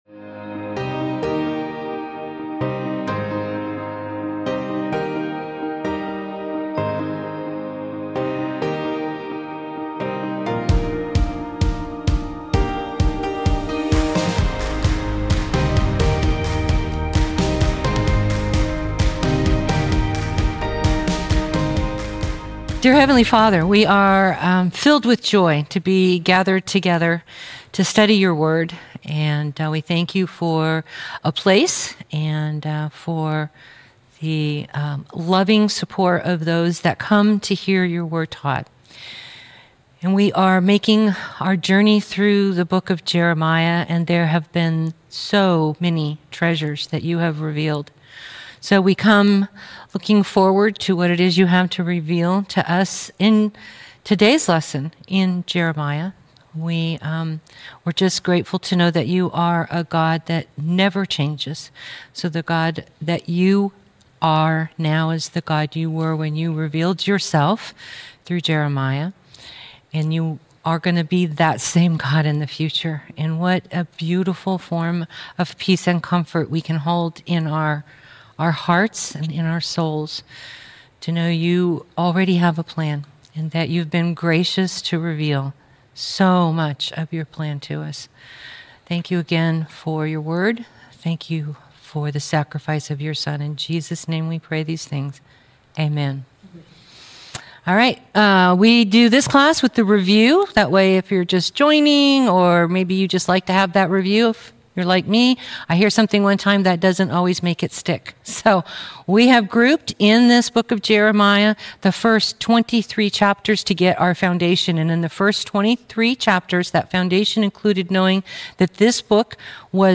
Jeremiah - Lesson 32B | Verse By Verse Ministry International